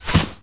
bullet_impact_snow_08.wav